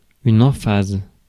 Ääntäminen
Synonyymit affectation exagération grandiloquence pathos ampoule Ääntäminen France: IPA: [ɑ̃.faz] Haettu sana löytyi näillä lähdekielillä: ranska Käännös Substantiivit 1. ilukõnelisus Suku: f .